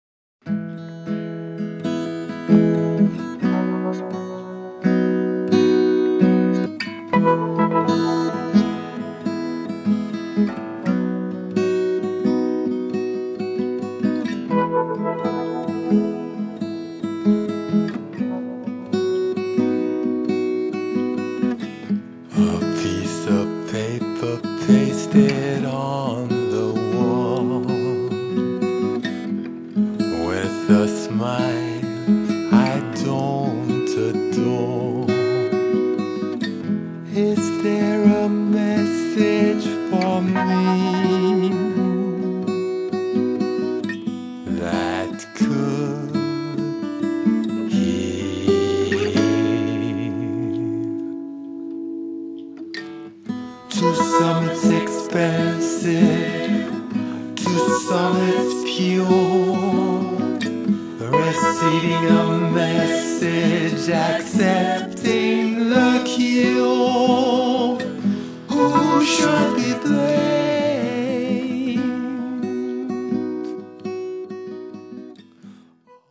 vocals
Bass
guitar
washing machine loop